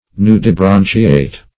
Nudibranchiate \Nu`di*bran"chi*ate\
nudibranchiate.mp3